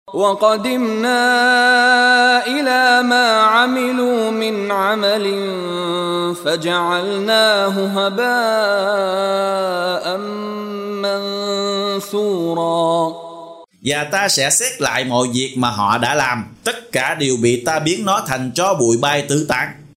Đọc ý nghĩa nội dung chương Al-Furqan bằng tiếng Việt có đính kèm giọng xướng đọc Qur’an